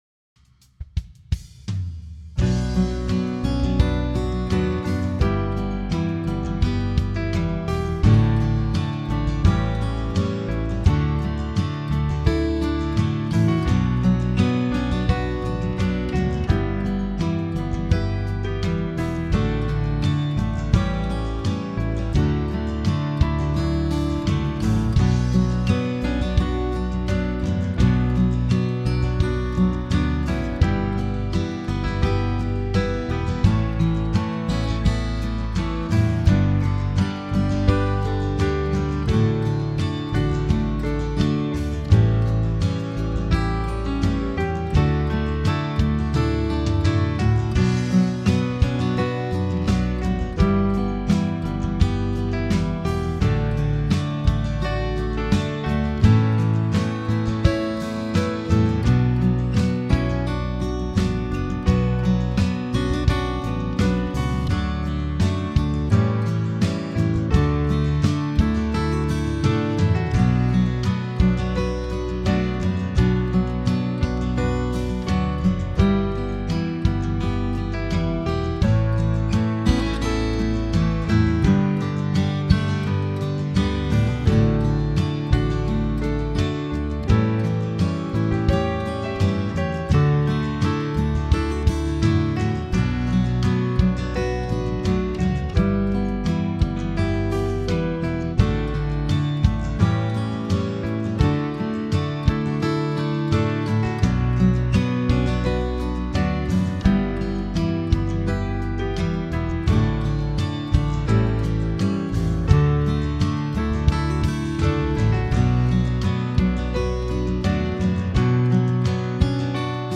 Home > Music > Pop > Smooth > Medium > Laid Back